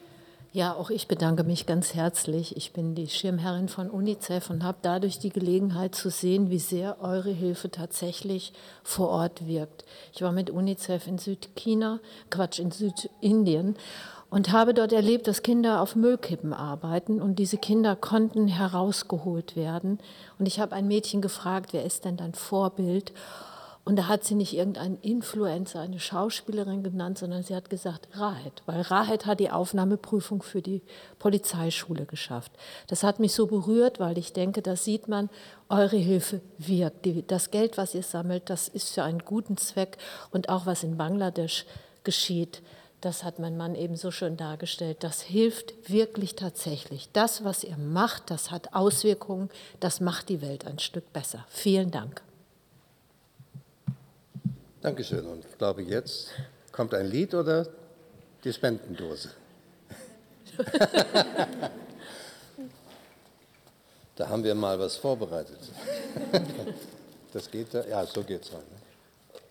Empfang beim Bundespräsidenten
20 Königinnen und Könige aus dem Erzbistum Paderborn haben pünktlich zum Dreikönigsfest am Dienstag, 6. Januar, Bundespräsident Frank-Walter Steinmeier und seine Frau Elke Büdenbender in Berlin besucht. Frau Büdenbender bedankte sich bei den Sternsingern.